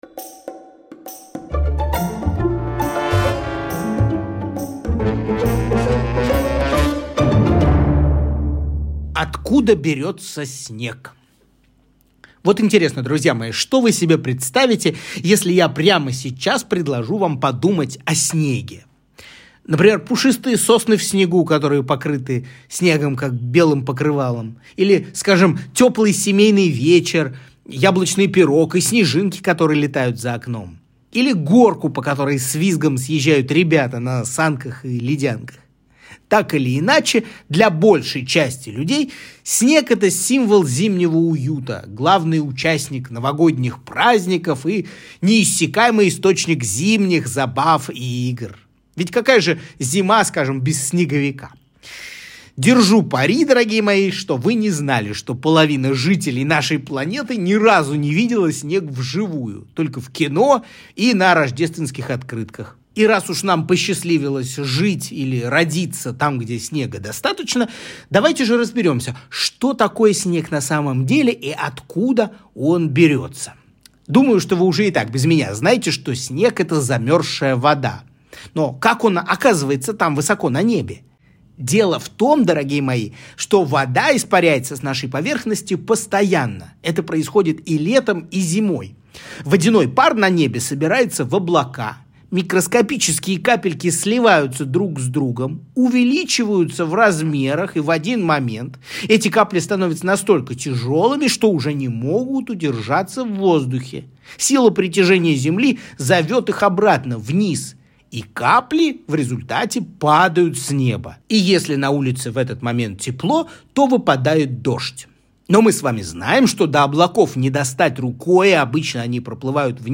Откуда берётся снег? Лекция